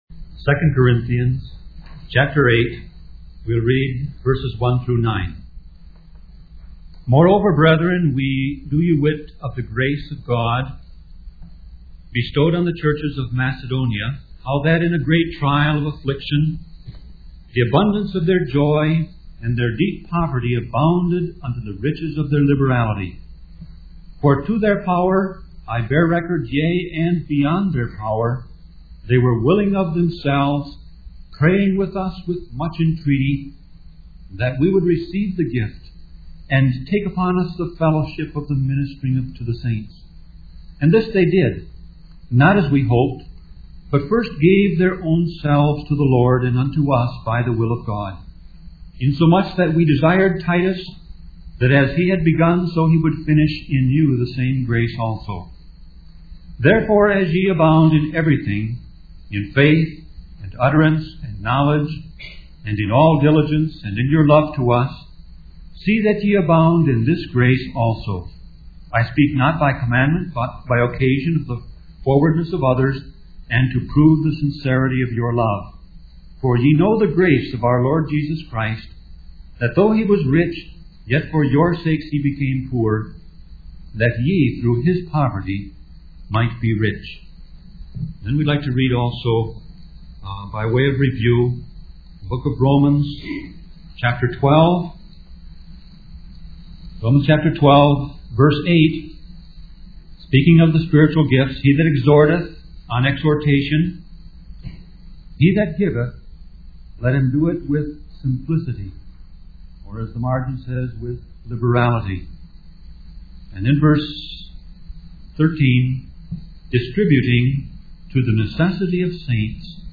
Sermon Audio Passage: 2 Corinthians 8:1-9